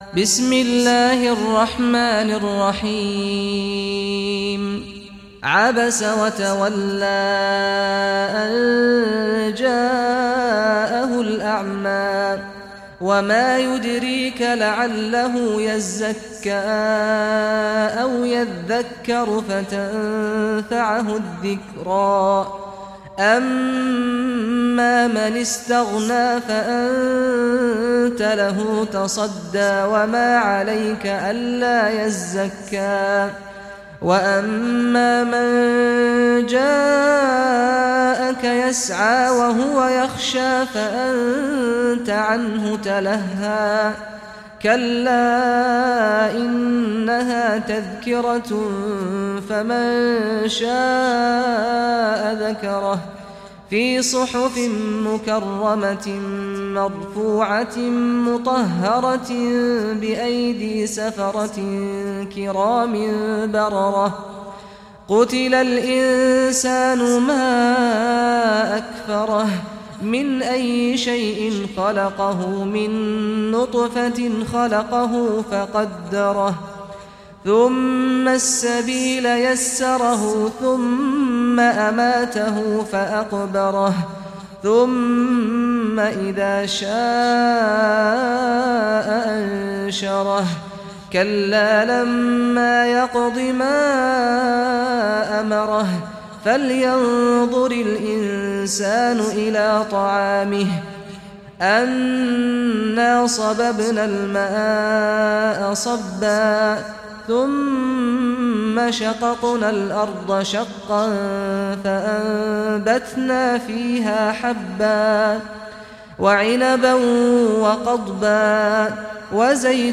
Surah Abasa Recitation by Sheikh Saad al Ghamdi
Surah Abasa, listen or play online mp3 tilawat / recitation in Arabic in the beautiful voice of Sheikh Saad al Ghamdi.